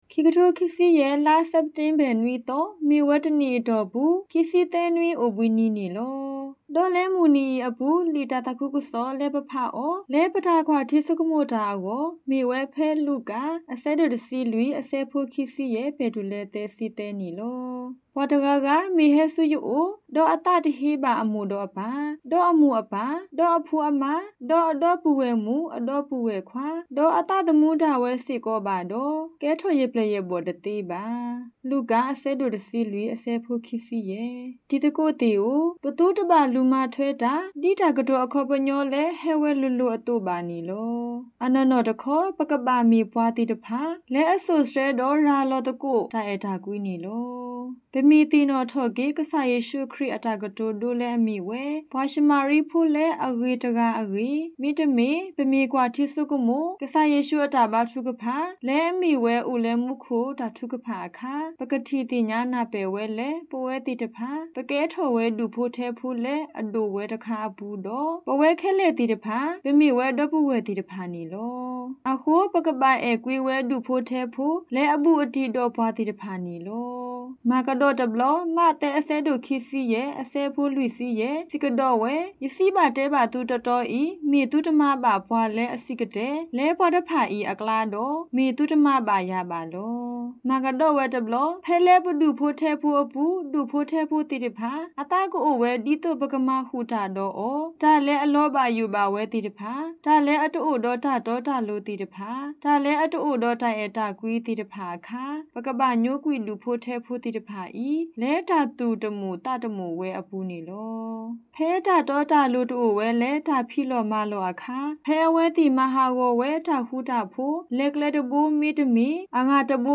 Sunday-Homily.mp3